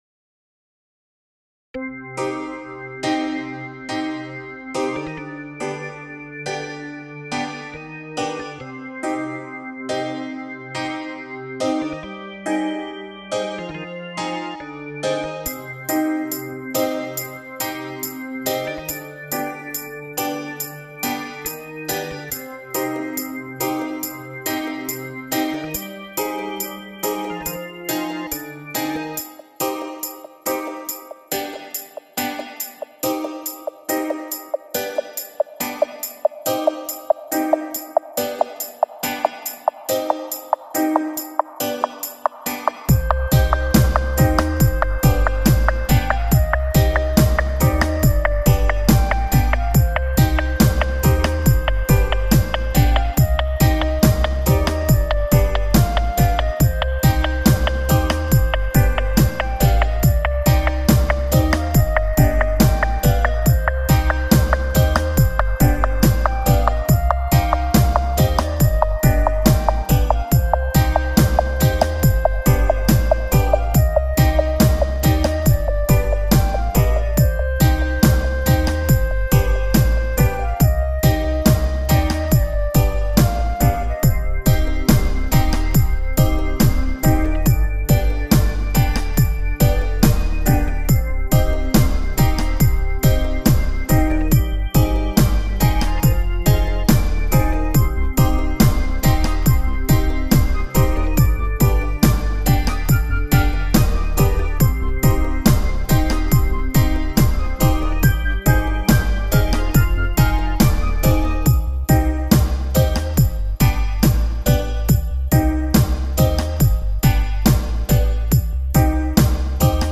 Jumping Lion Sound home-made tune ask for dub or others tunes and versions